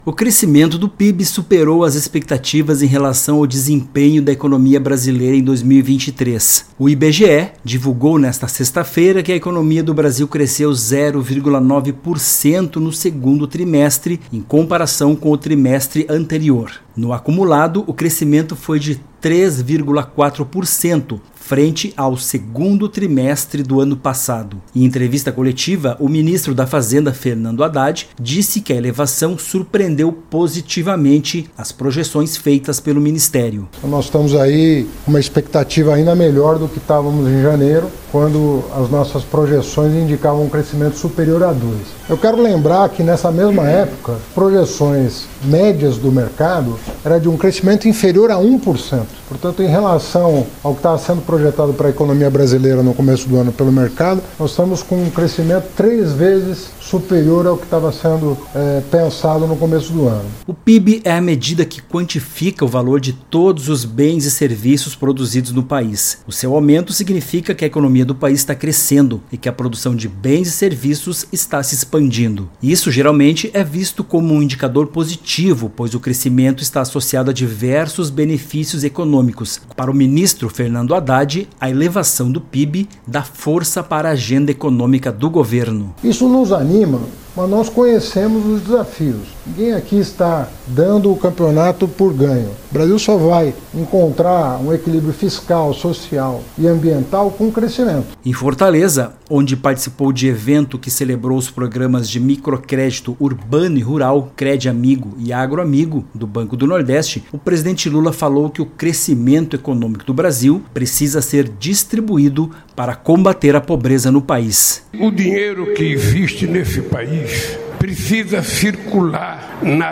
Boletins de Rádio